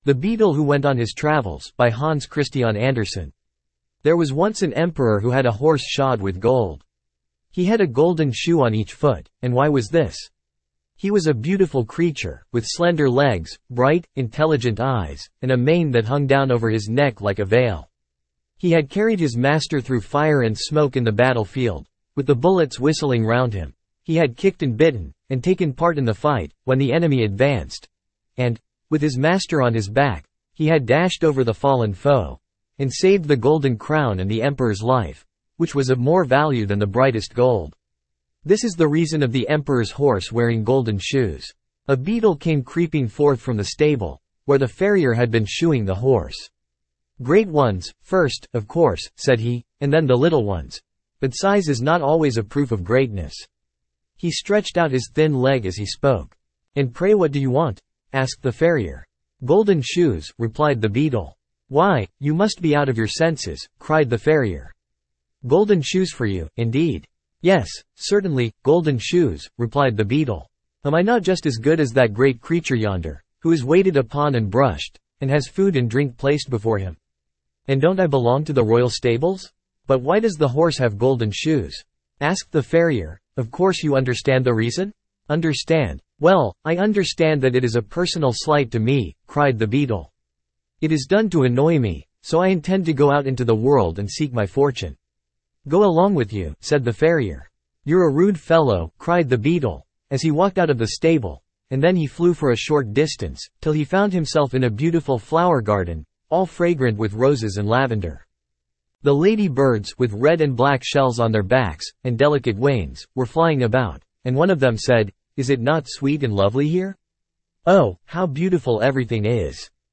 Spoken Ink « Fairy Tales of Hans Christian Andersen The Beetle Who Went On His Travels Standard (Male) Download MP3 There was once an Emperor who had a horse shod with gold.